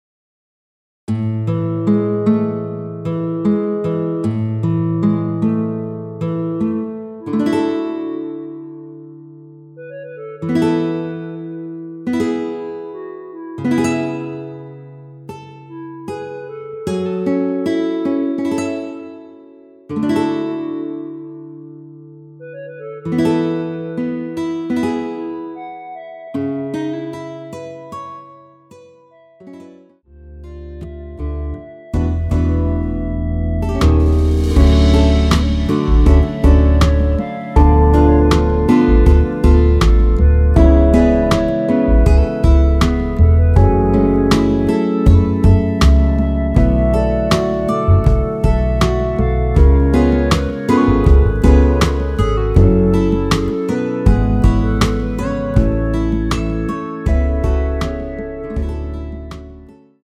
전주 없이 시작하는 곡이라 라이브 하기 좋게 전주 2마디 만들어 놓았습니다.(미리듣기 확인)
원키에서(-6)내린 멜로디 포함된 MR입니다.
앞부분30초, 뒷부분30초씩 편집해서 올려 드리고 있습니다.